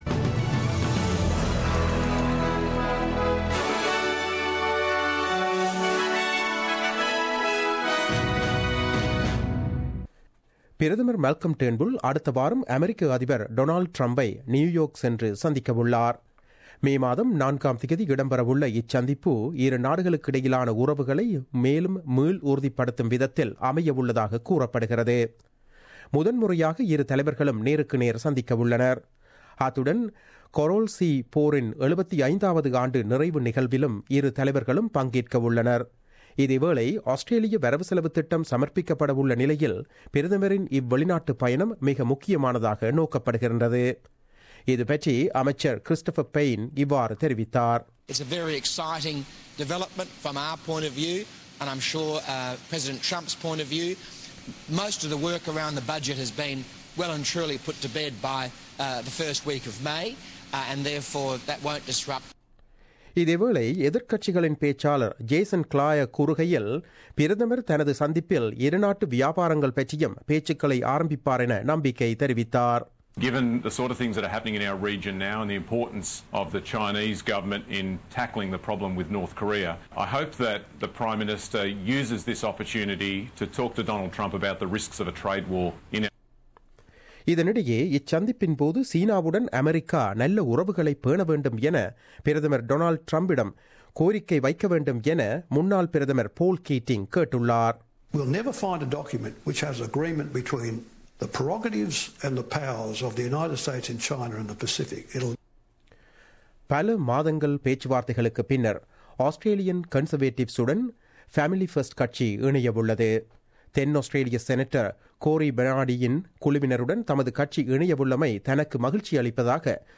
The news bulletin aired on Wednesday 26 April 2017 at 8pm.